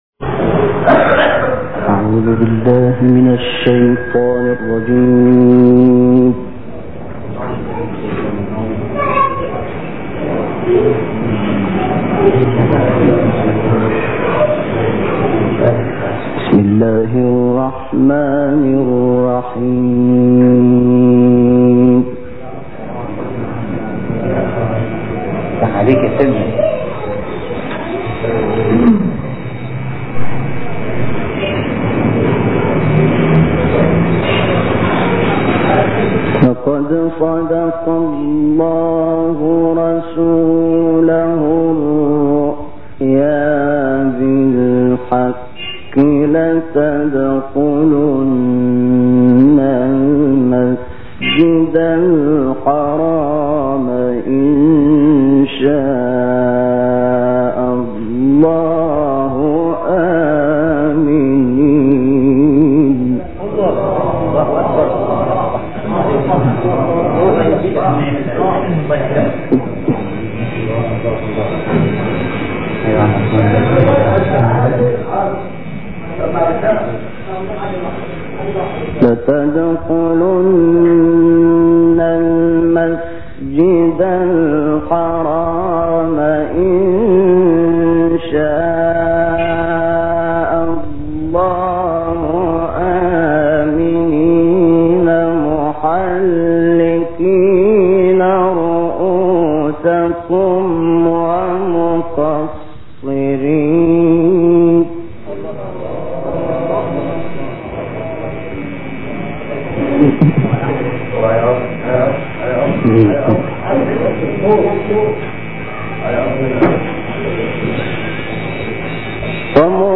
تلاوت‌‌ شیخ شحات محمد انور از آیات 27 تا 29 سوره فتح و آیات 1 تا 10 سوره مبارکه حجرات اجرا شده در سال 1986 در مصر را می‌شنوید.
تلاوت شحات محمد انور از آیات پایانی سوره فتح 1986 مصر